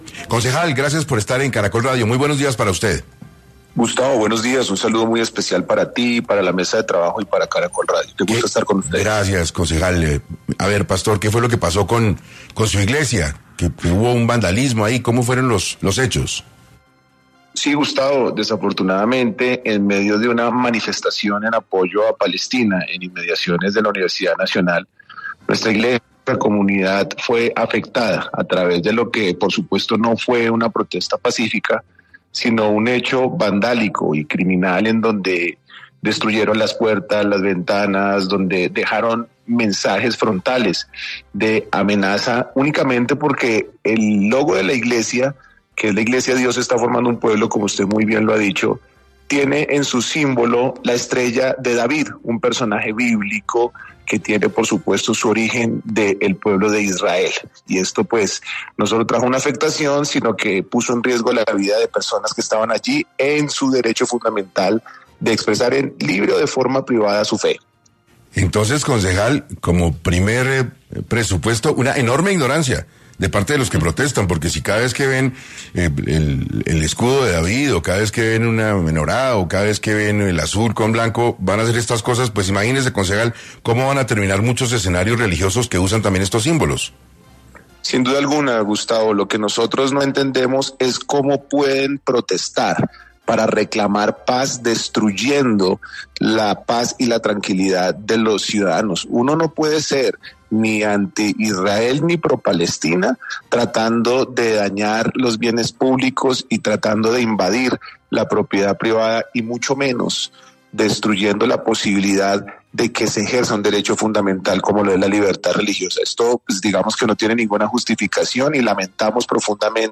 Marco Acosta, concejal de Bogotá denuncia en 6AM actos vandálicos en la Congregación cristiana ‘Dios está formando un Pueblo’, luego de las manifestaciones a favor de Palestina.